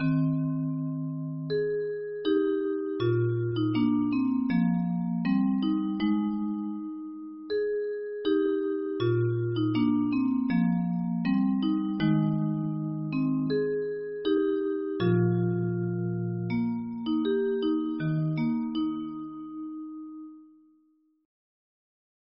- Suitable for 18 note music box mechanism,
- Music box song lyrics